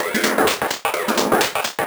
Index of /VEE/VEE Electro Loops 128 BPM
VEE Electro Loop 076.wav